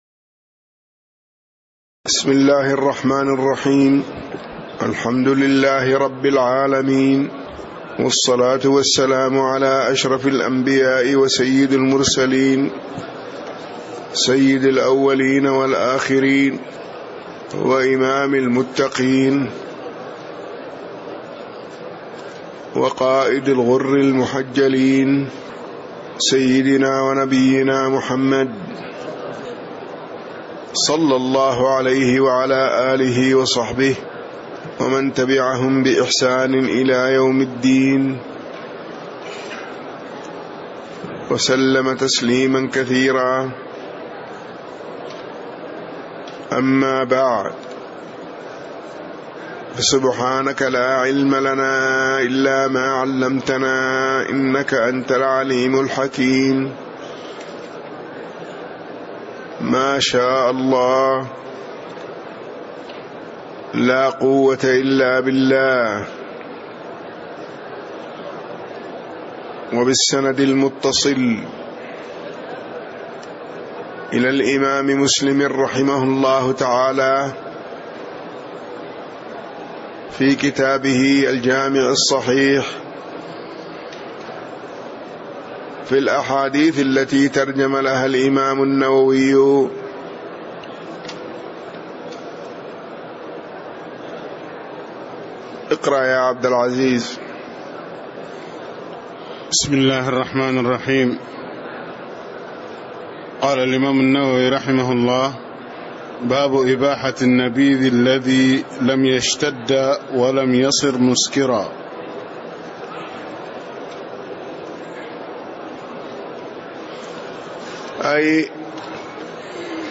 تاريخ النشر ٧ رجب ١٤٣٦ هـ المكان: المسجد النبوي الشيخ